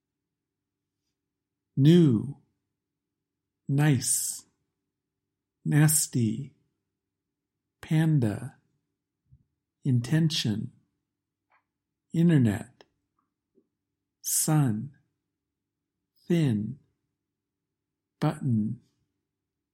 You make these sounds by letting the air go through your nose.
The /n/ sound